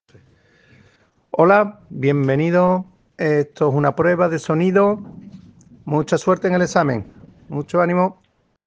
PRUEBA DE SONIDO.